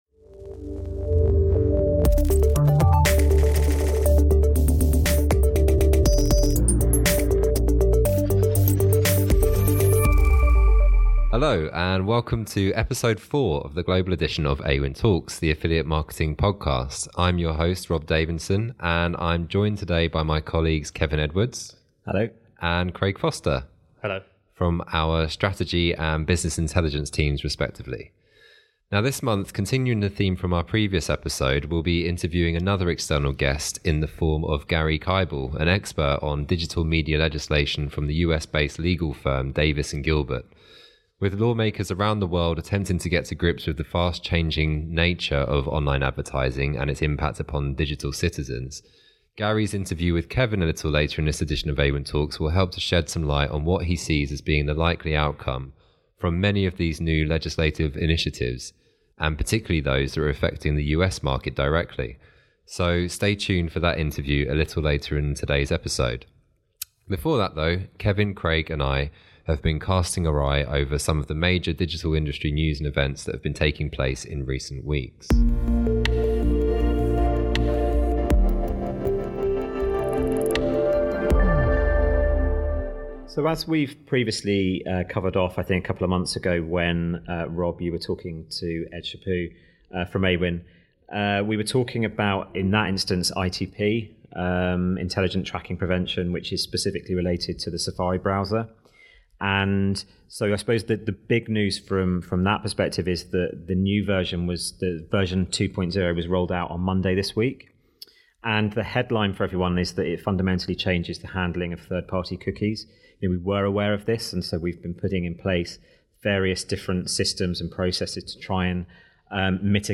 The team also discuss the case of the Italian man recently jailed for selling fraudulent reviews on TripAdvisor, as well as the news that some Amazon employees have been bribed to delete negative product reviews.